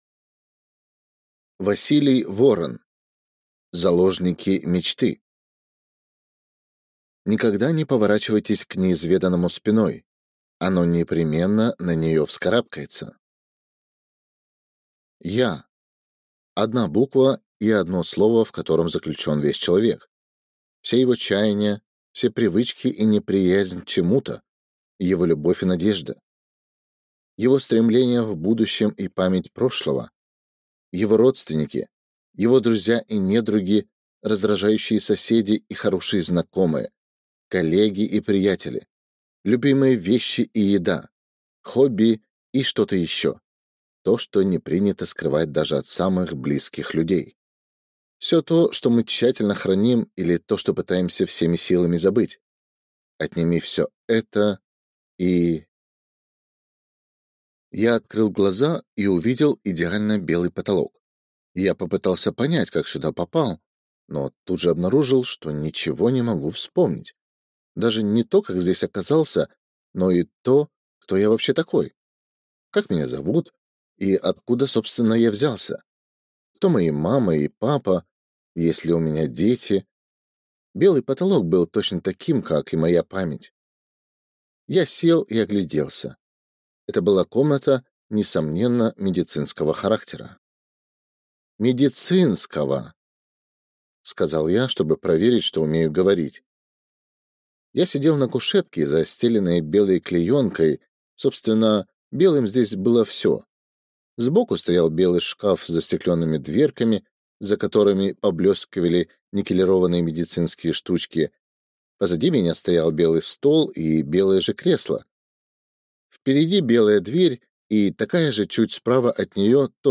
Aудиокнига Заложники мечты